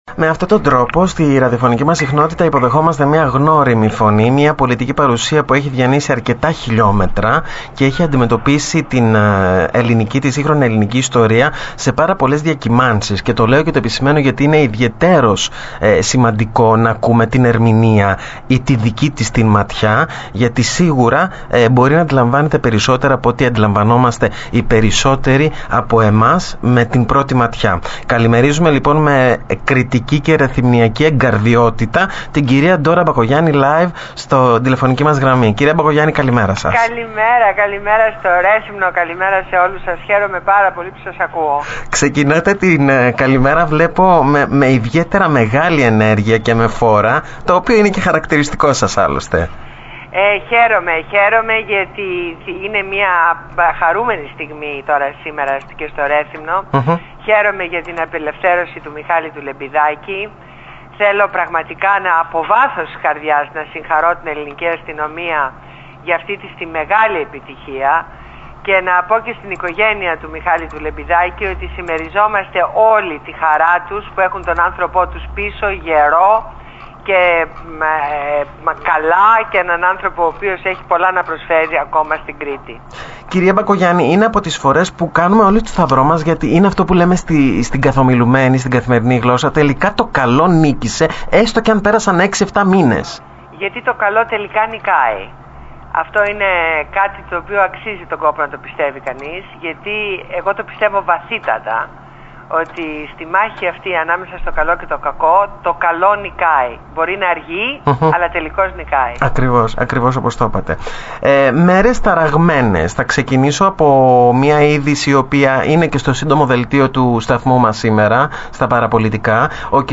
Συνέντευξη στο ραδιόφωνο Παραπολιτικά Κρήτης 102,4fm